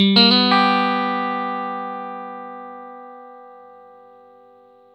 RIFF1-120G.A.wav